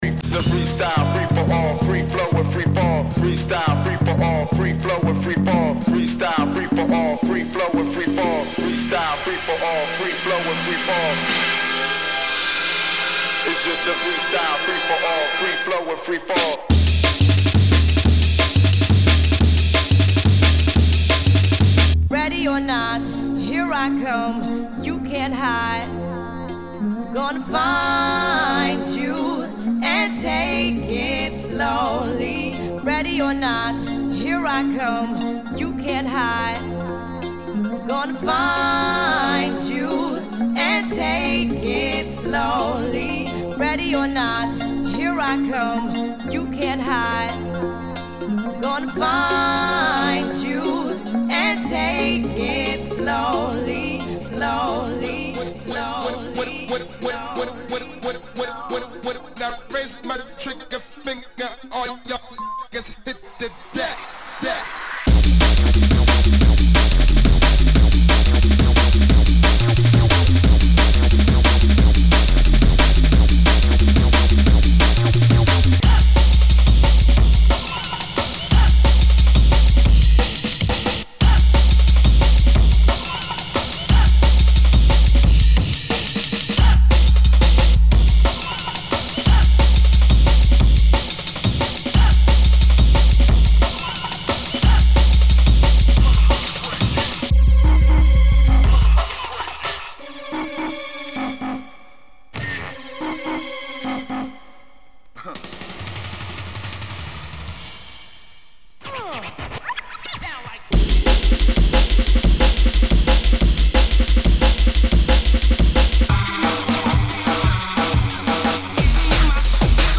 wurde dieses Mixtape im Mai 2004 fertig gestellt.
Drum & Bass
Snippet Side B